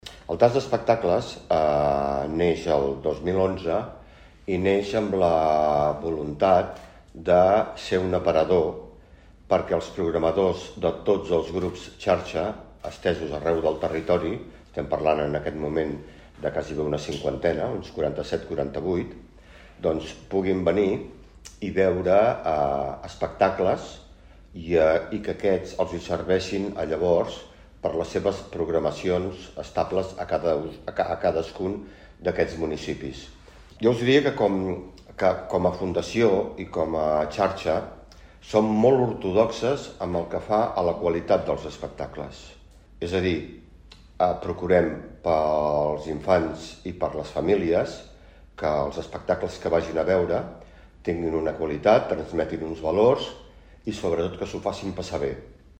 Roda de Premsa presentació 14è Tast d'Espectacles Familiars